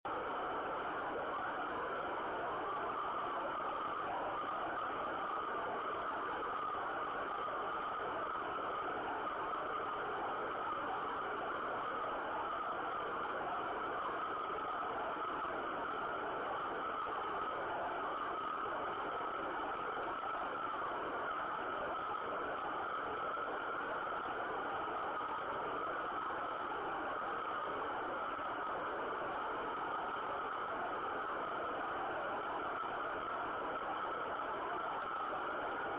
Pozorně si poslechněte, jak vypadají signály z pásma 24GHz, které přišly z Texasu odrazem od Měsíce. Charakteristický je jejich syčivý tón, vzniklý odrazy - vlnová délka je 1,5cm!
s vyšším tónem